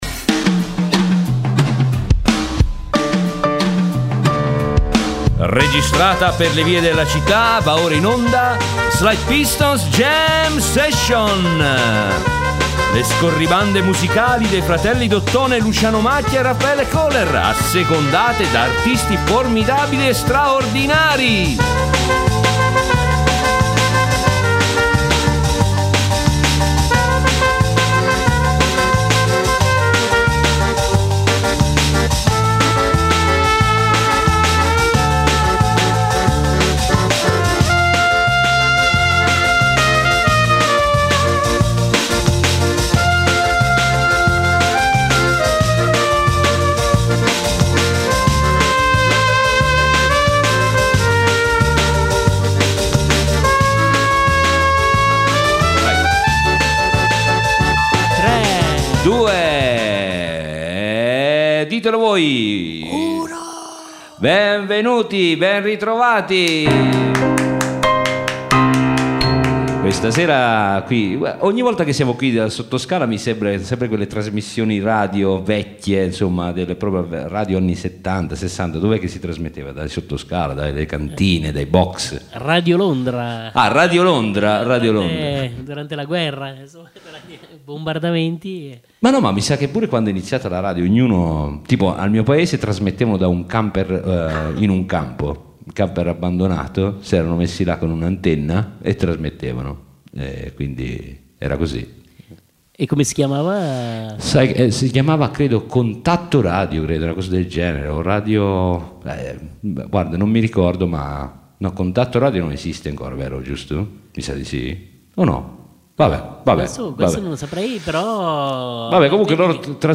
In onda le scorribande musicali dei due suonatori d’ottone in giro per la città, assecondate da artisti formidabili e straordinari.